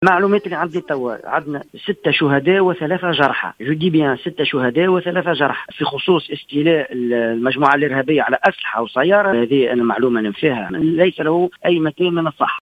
وأوضح في تصريح للجوهرة اف ام، أن العملية أسفرت عن استشهاد 6 أعوان وإصابة 3 آخرين.